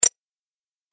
دانلود آهنگ کلیک 44 از افکت صوتی اشیاء
جلوه های صوتی
دانلود صدای کلیک 44 از ساعد نیوز با لینک مستقیم و کیفیت بالا